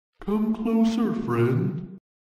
На этой странице собрана коллекция звуков и голосовых фраз Haggy Wagy.